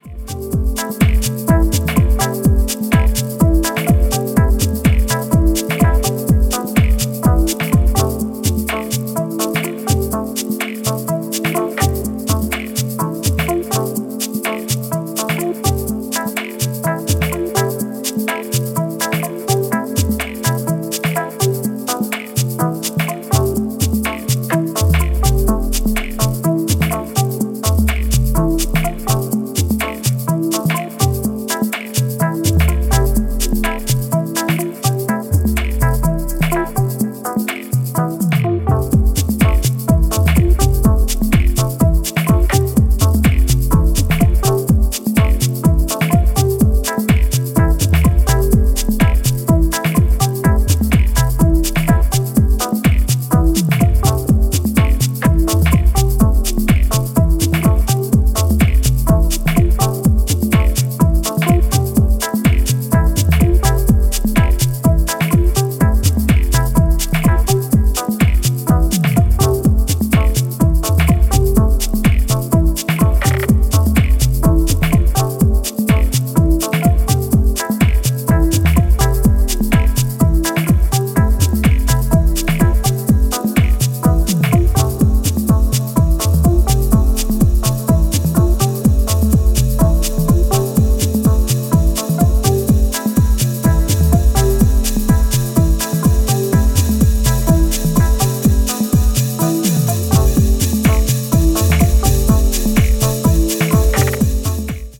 supplier of essential dance music